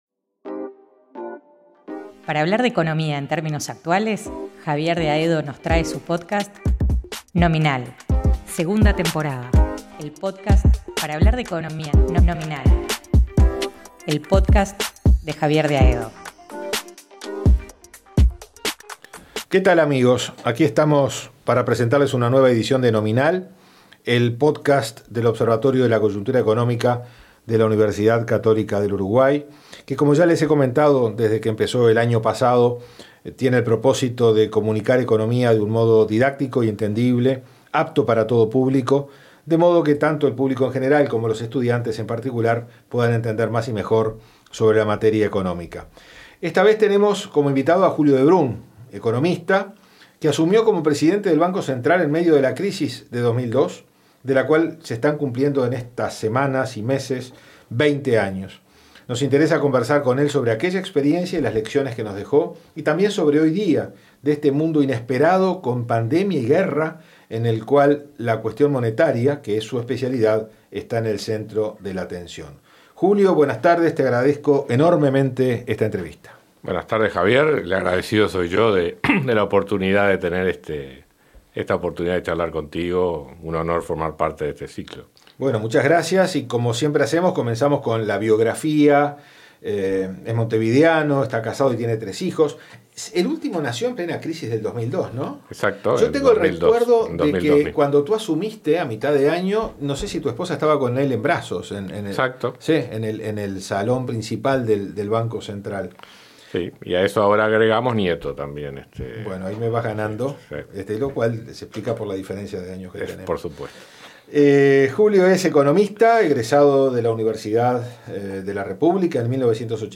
¿Qué lecciones se aprendieron de aquel entonces? ¿Cómo ve al Uruguay de hoy ante un contexto internacional entre pandemia y guerra? Análisis, anécdotas y hasta su vínculo con la Selección Uruguaya de 1930 en esta entrevista.